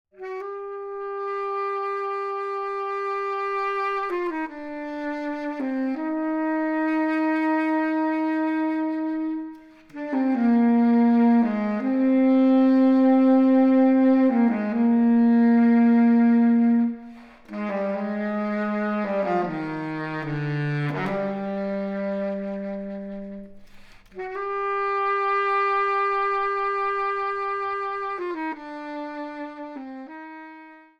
Verbtone – Physical modeled plate reverb – Smooth and deep
Sax_small_room
Verbtone_Sax_Small.wav